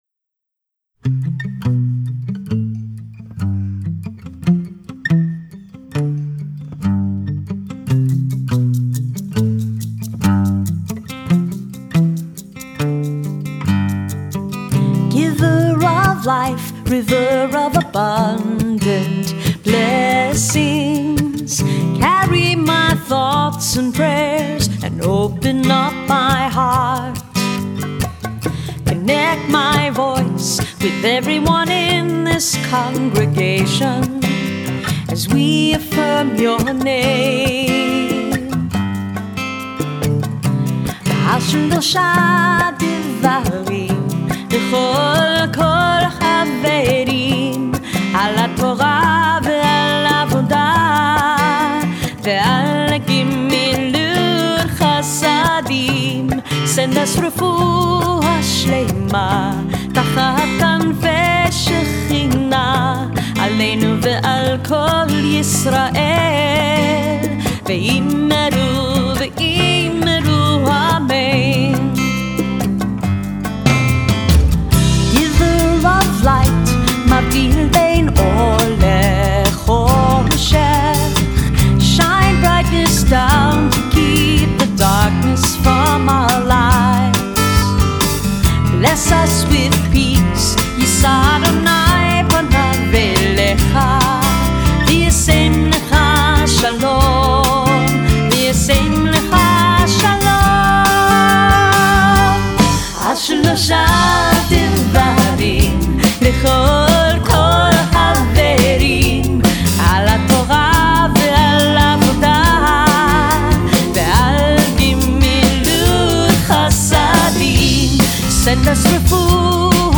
contemporary Shabbat music